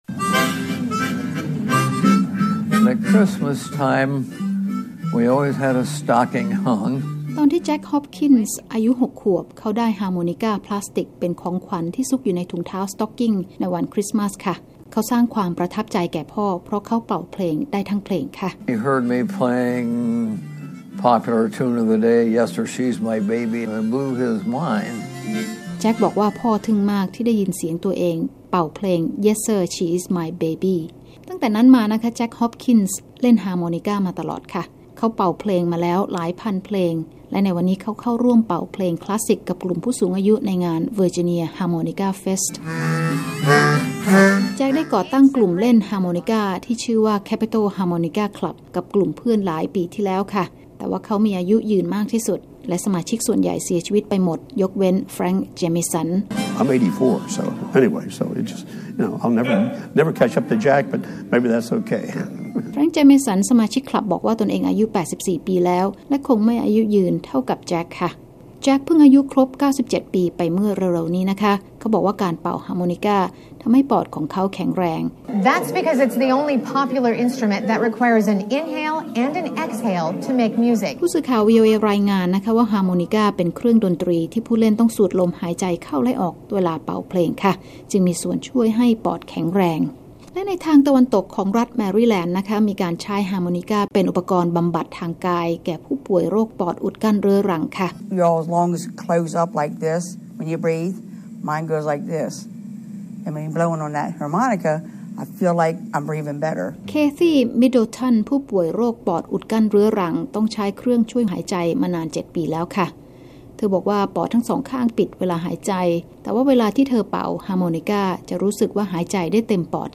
เป่าฮาร์โมนิก้าเพื่อสุขภาพ